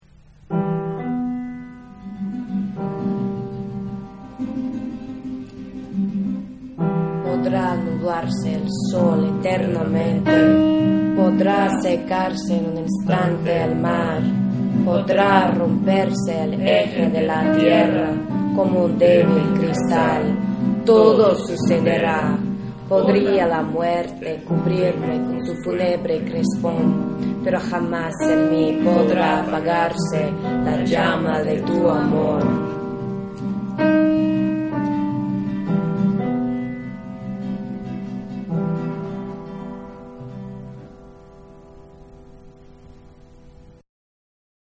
Our most multilingual poem-recording session ever. Unmastered, tracks just thrown together–but still sounding awesome.